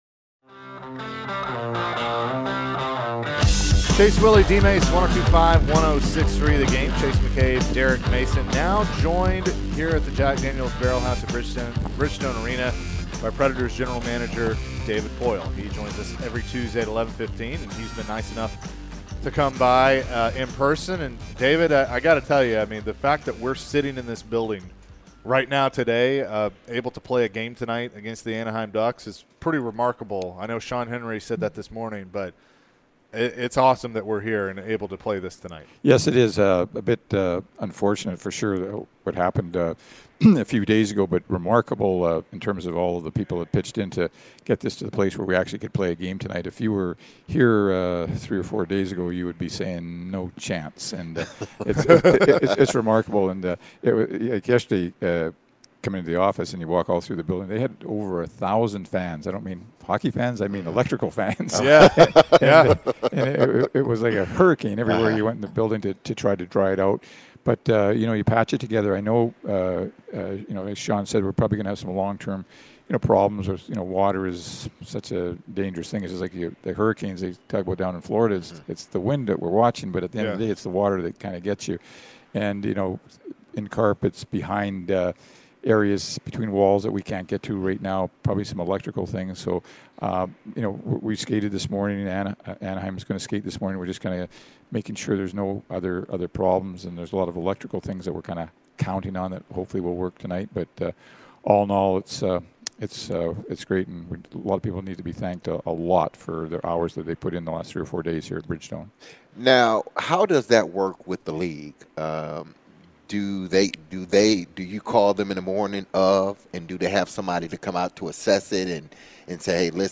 David Poile Interview (11-29-22)